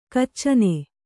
♪ kaccane